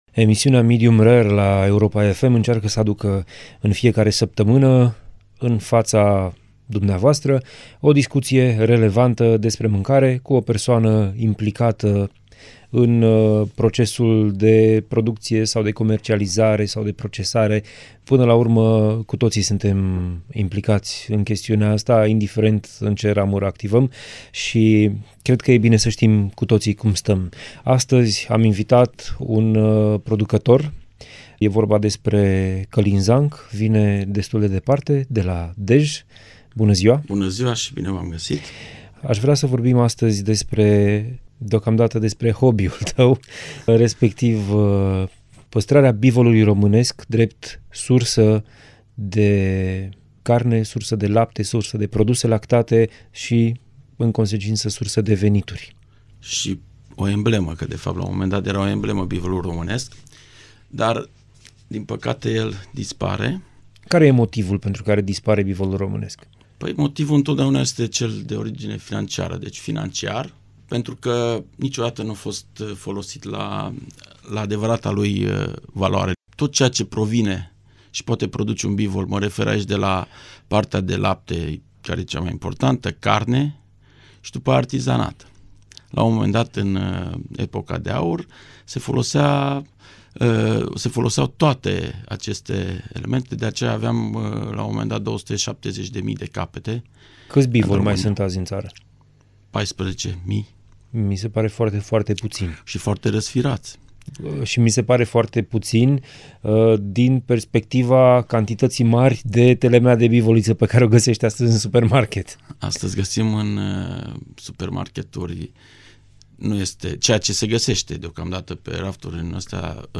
INTERVIUL COMPLET